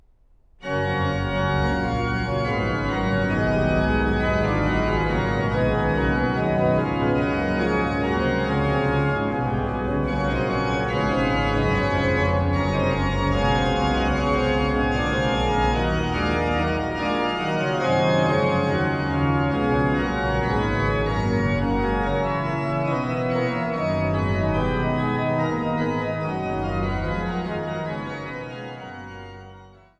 an der Großen Silbermann-Orgel im Freiberger Dom
Orgel